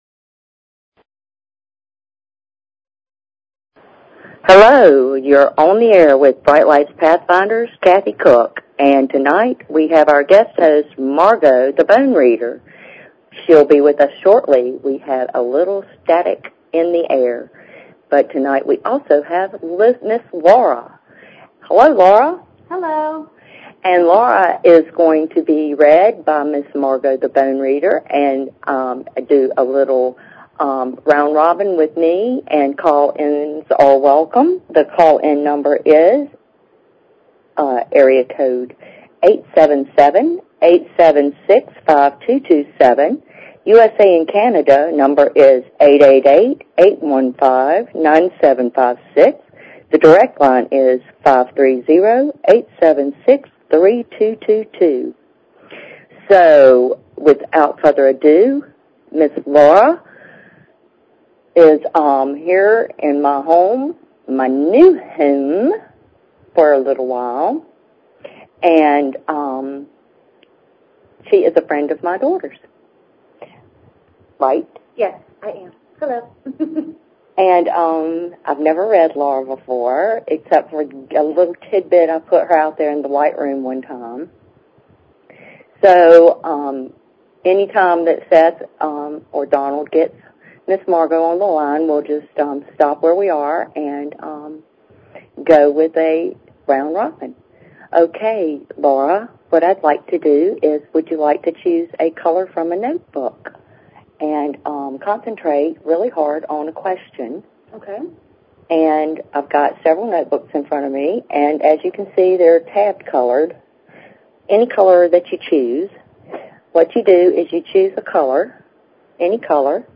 Talk Show Episode, Audio Podcast, Brightlights_Pathfinders and Courtesy of BBS Radio on , show guests , about , categorized as